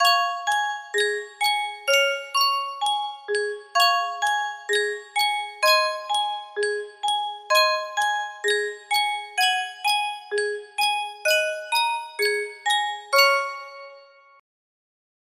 Sankyo Music Box - Tom Dooley UFP music box melody
Full range 60